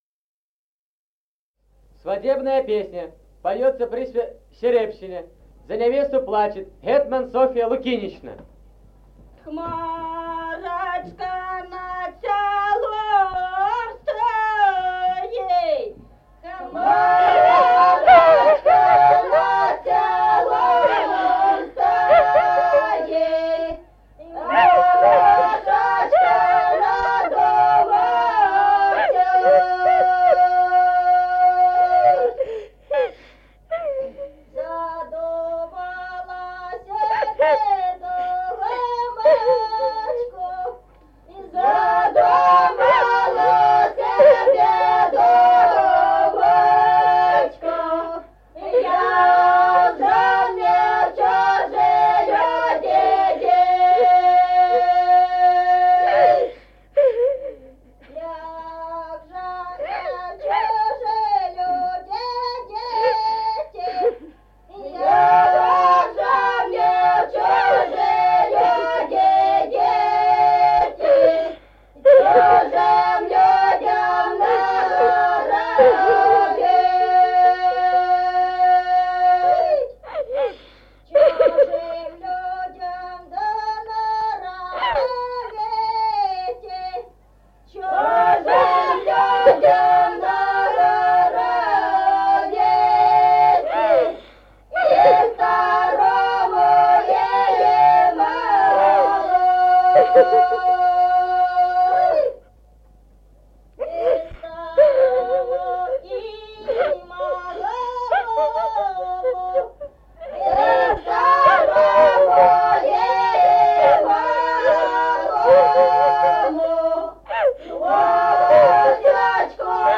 Народные песни Стародубского района «Хмарочка над селом», «сваде́бная», с плачем невесты.
1953 г., с. Мишковка.